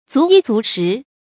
足衣足食 注音： ㄗㄨˊ ㄧ ㄗㄨˊ ㄕㄧˊ 讀音讀法： 意思解釋： 衣食豐足。